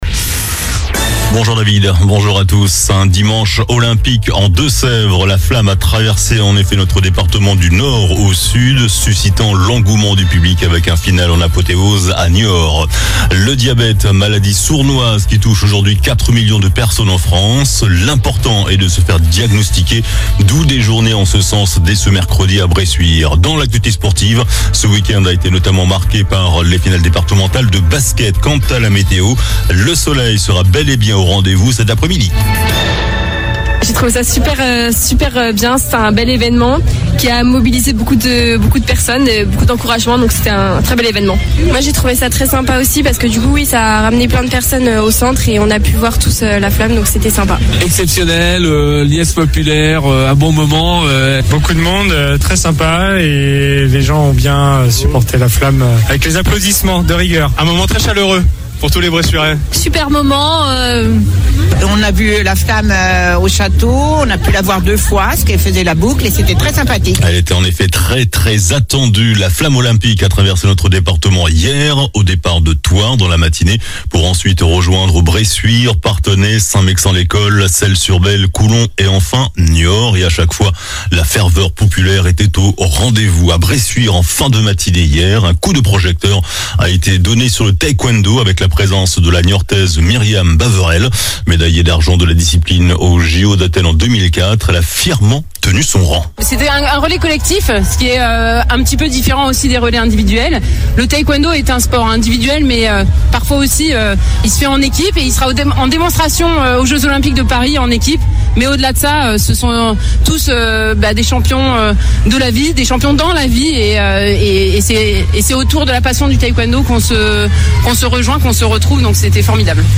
JOURNAL DU LUNDI 03 JUIN ( MIDI )